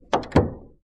Part_Assembly_04.wav